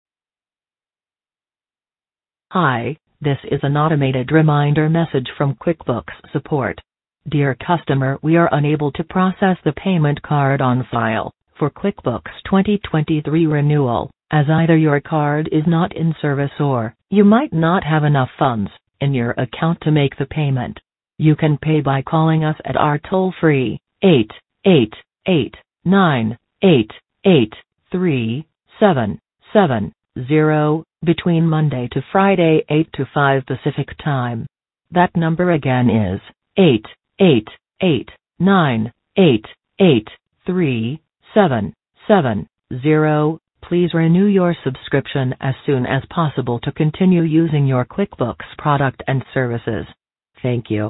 Robocall :arrow_down: